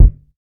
TC Kick 22.wav